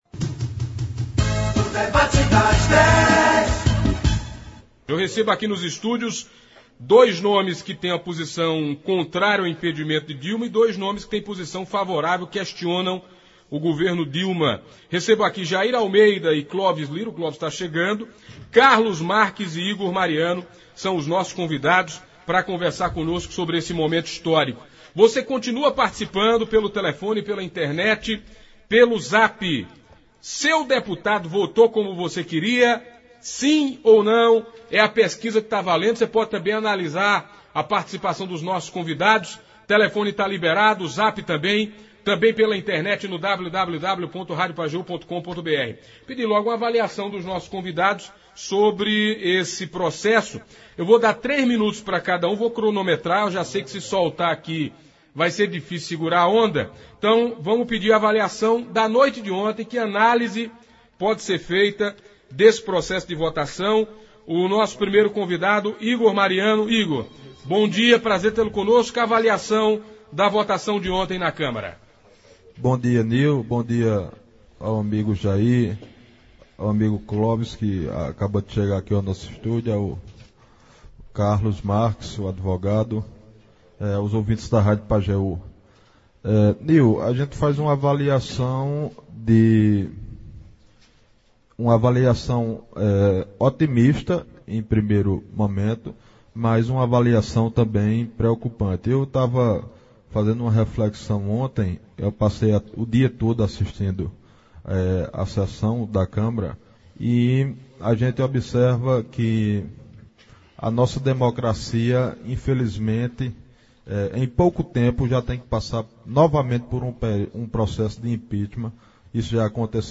Foi um debate polêmico, com ambas as partes defendendo suas posições. Ao final do debate uma acalorada discussão entre os dois advogados foi o ponto alto do debate.
Ouça na íntegra o debate de hoje: